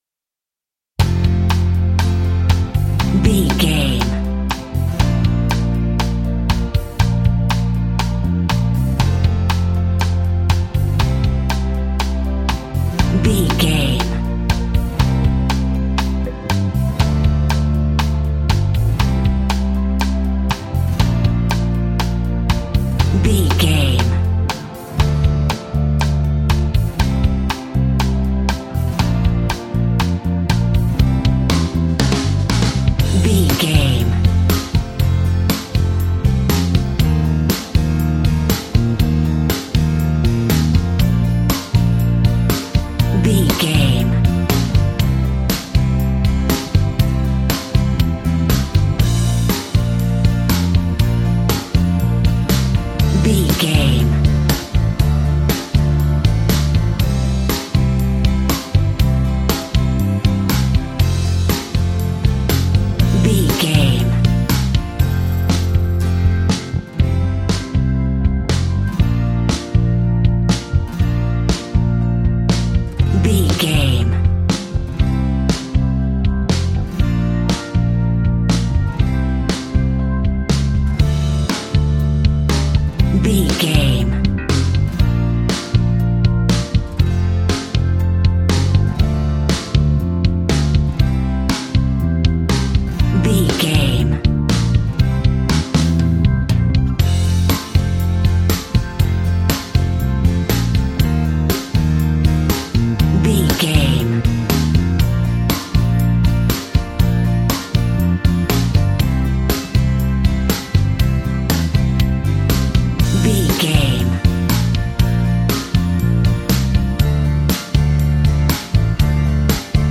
Ionian/Major
pop rock
indie pop
energetic
uplifting
upbeat
groovy
guitars
bass
drums
organ